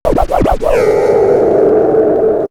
Scratch 29.wav